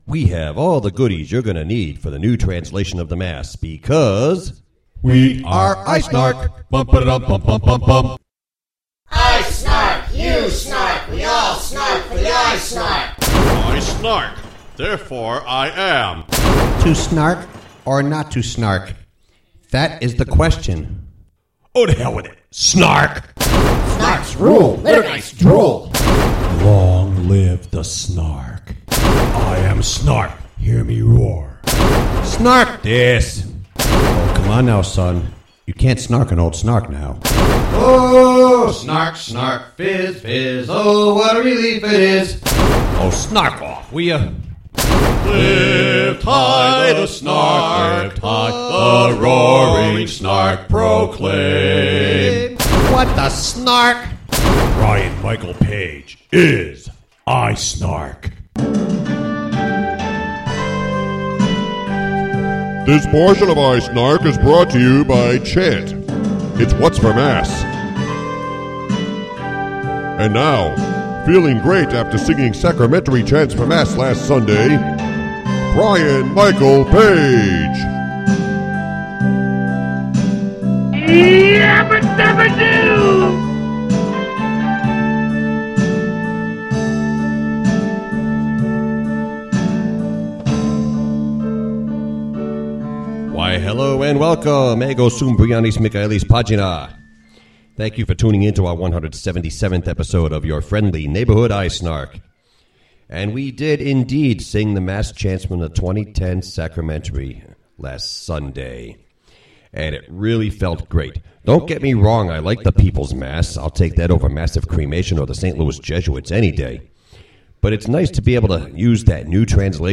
Related Link: The Prince and the Composer, Part 1 (Prince Charles on Charles Hubert Hastings Parry) Miscellaneous Outburst Music: 1-4. Roman Missal chants (1.
Ubi Caritas (Chant, Mode VI) 6.